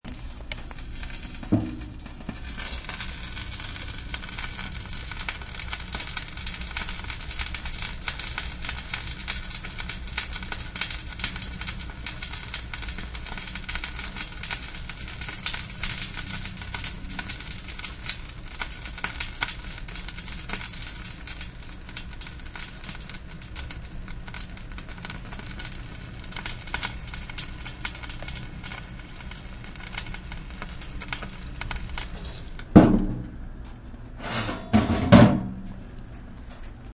Download Roasting sound effect for free.
Roasting